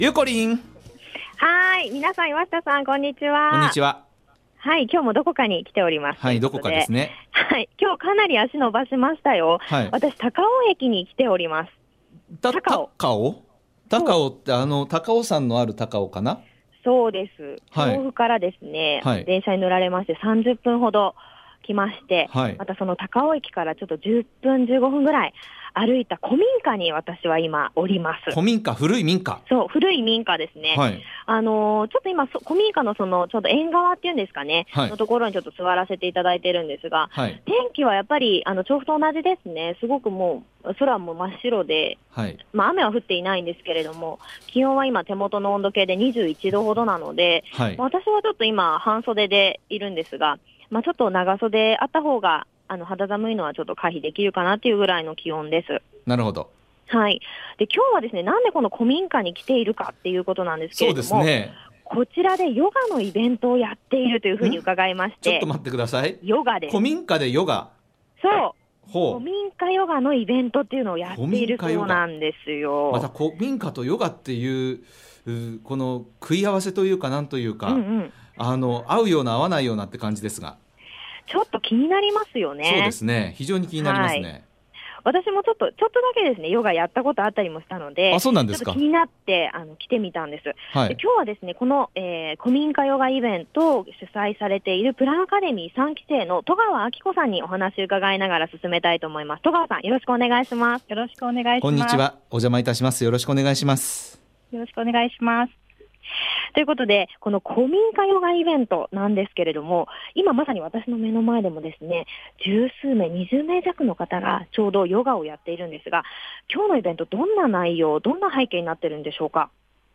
★びゅーサン 街角レポート
今日お邪魔したのは、高尾の古民家カフェ、TOUMAIエスニックカフェでヨガのイベントをやっていると伺って行ってきました☆ヨガは今や、女性なら体験したことが一度はあるのでは？というくらいメジャーな健康法になりましたよね。